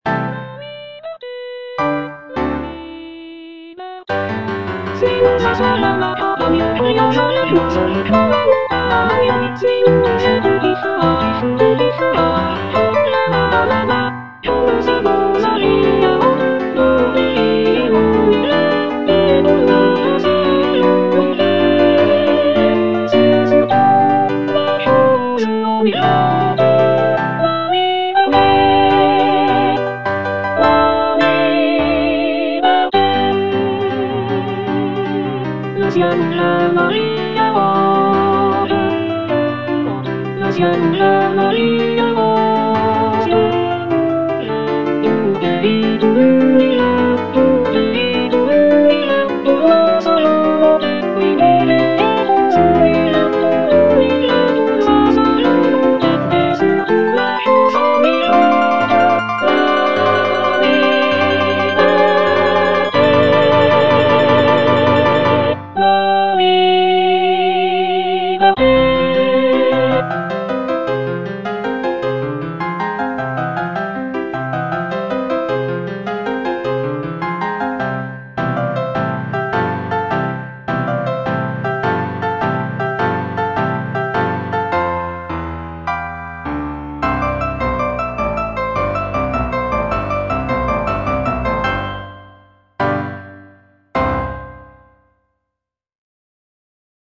G. BIZET - CHOIRS FROM "CARMEN" Suis-nous à travers la campagne (soprano I) (Emphasised voice and other voices) Ads stop: auto-stop